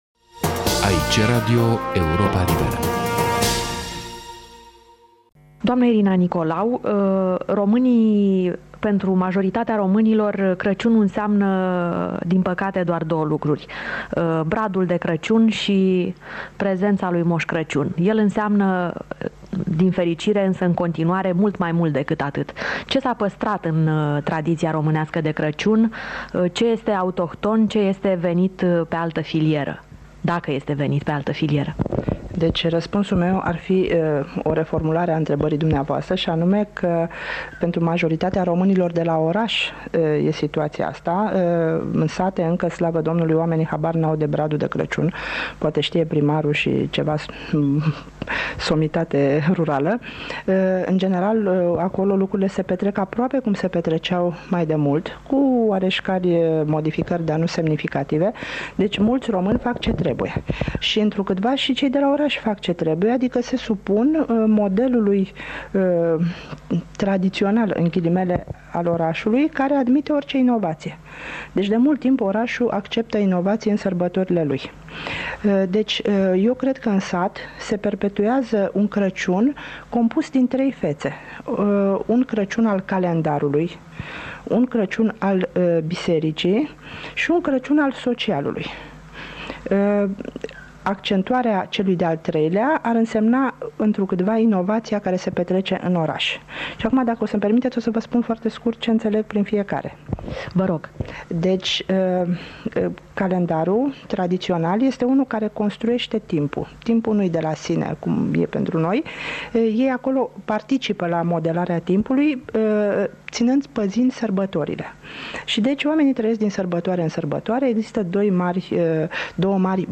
Un fragment dintr-o ediție specială de sărbători a emisiunii „„Actualitatea românească”, difuzată în 25 decembrie 1998.